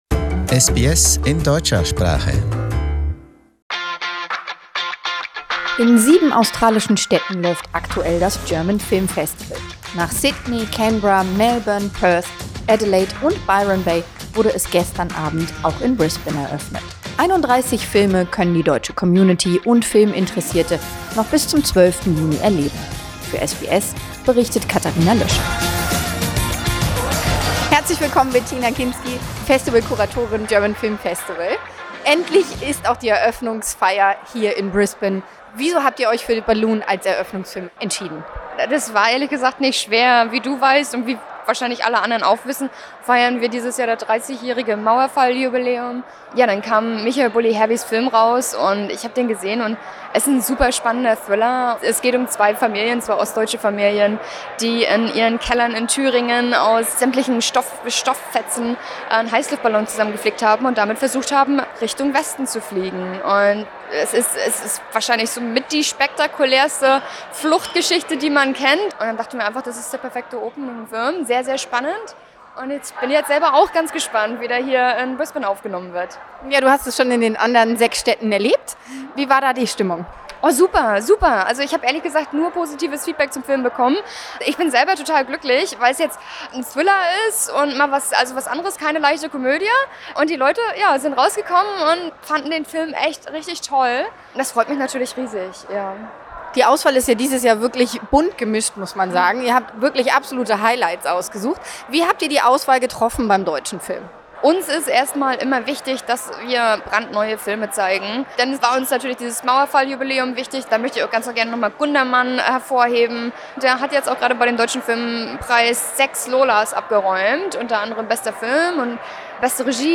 SBS German